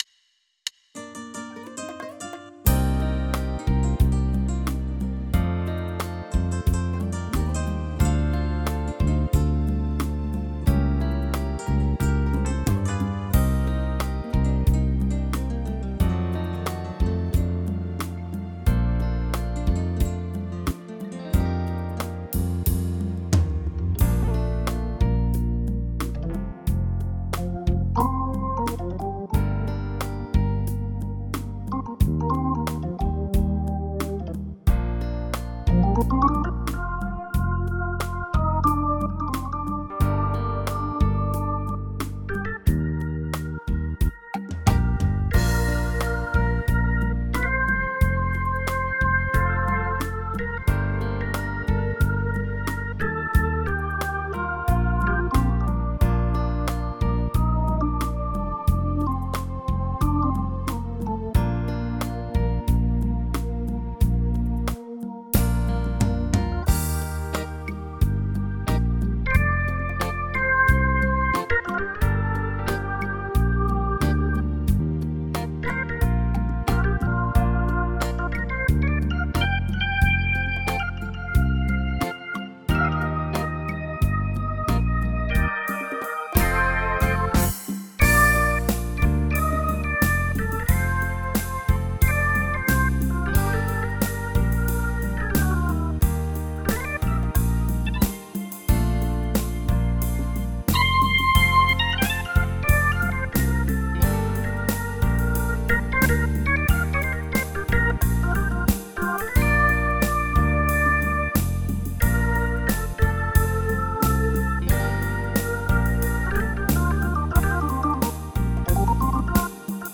Someone send me an organ sound for tyros 2 TVN sound
I converted it to SF2 and loaded it on vArranger
Added a bit of reverb and chorus
Loaded a Tyros 5 style, pressing MP3 REC and here is the result